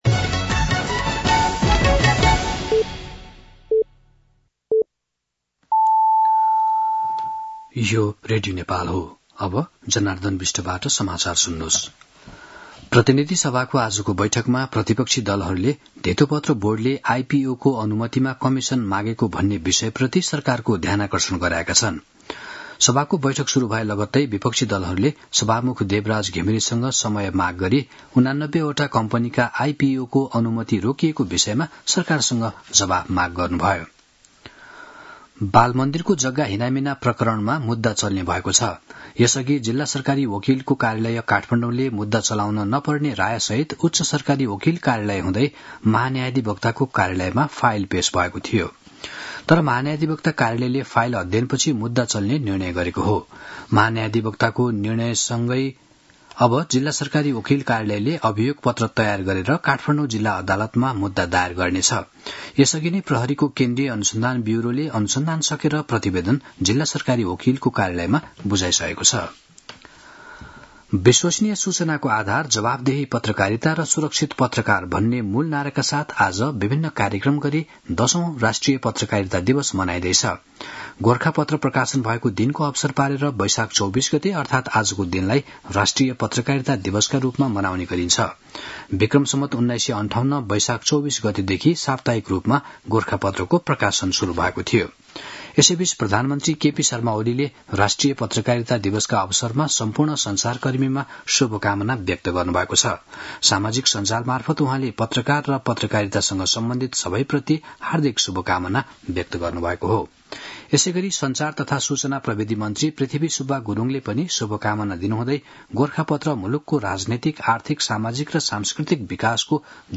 दिउँसो १ बजेको नेपाली समाचार : २४ वैशाख , २०८२